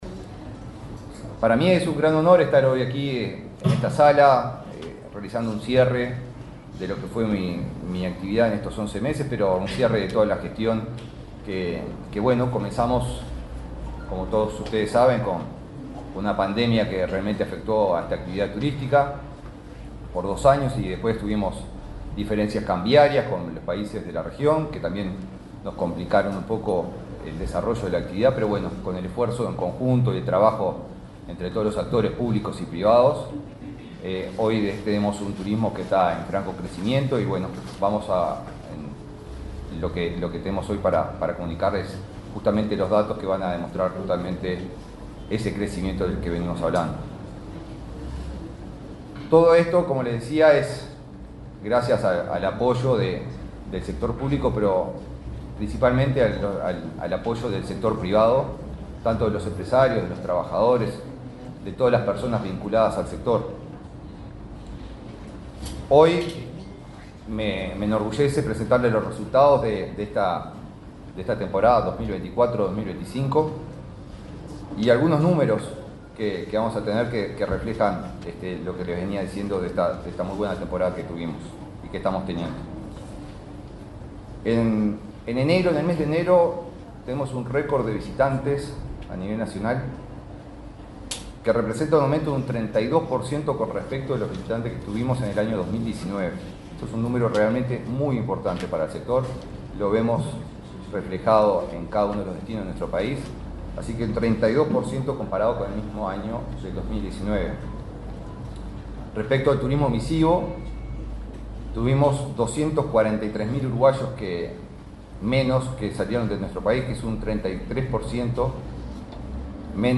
Palabras del ministro de Turismo, Eduardo Sanguinetti
Este miércoles 26 en Montevideo, el ministro de Turismo, Eduardo Sanguinetti, encabezó el acto de presentación del balance de gestión del quinquenio.